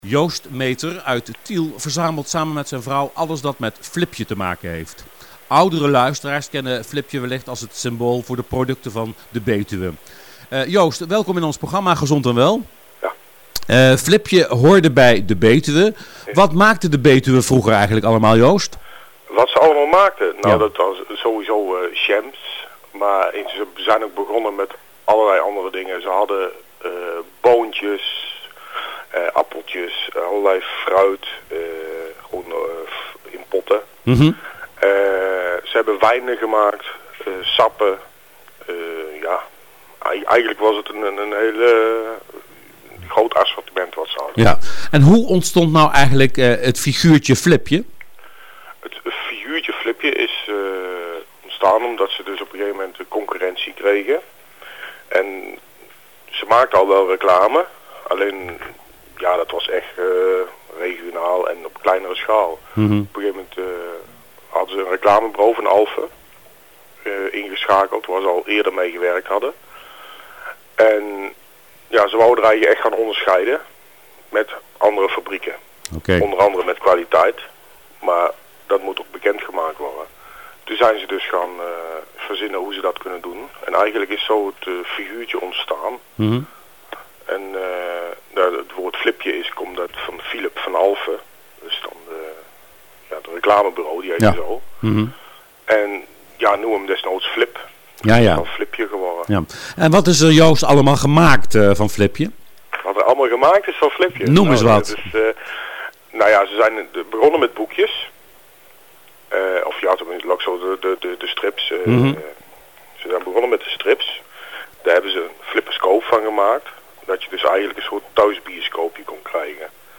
Interviews met verzamelaars en hobbyisten
Interview met verzamelaar/hobbyist in radiouitzendingen "Gezond en wel":